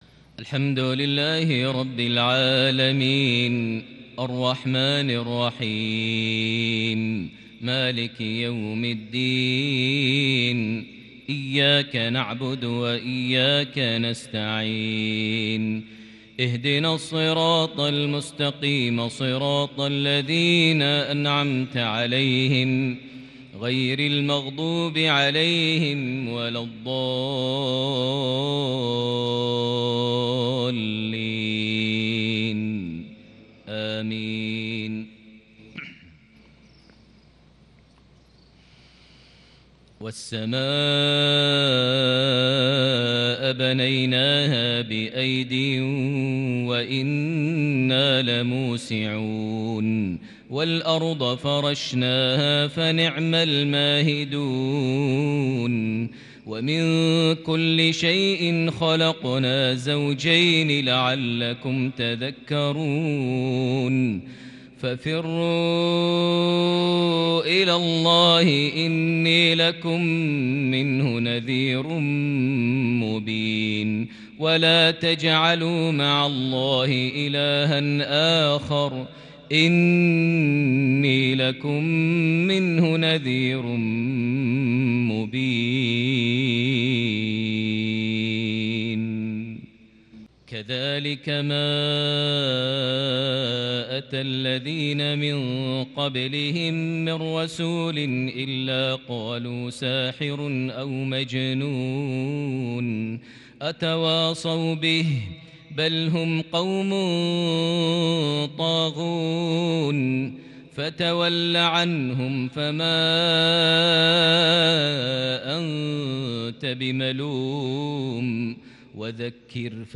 تلاوة مسترسلة لخواتيم سورة الذاريات | مغرب 3 صفر 1442هـ > 1442 هـ > الفروض - تلاوات ماهر المعيقلي